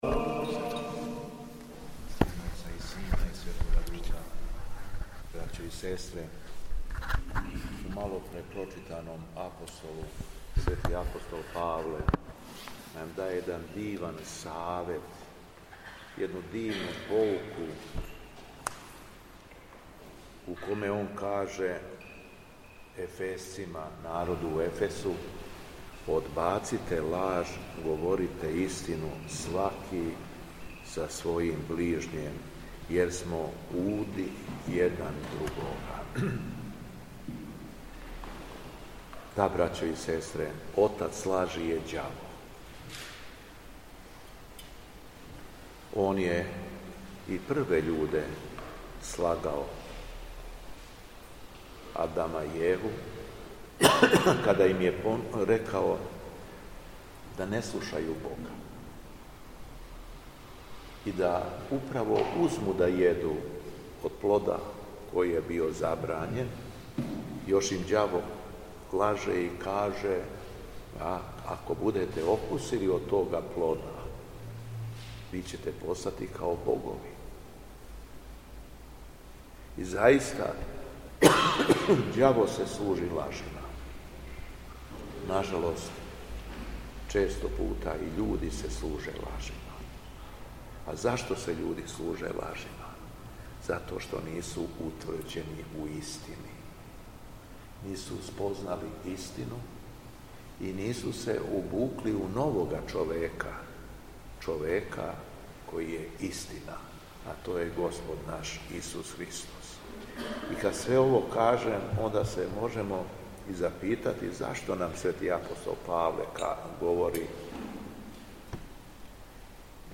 Беседа Његовог Високопреосвештенства Митрополита шумадијског г. Јована
После прочитаног јеванђелског зачала, Високопреосвећени Митрополит се обратио беседом сабраном народу: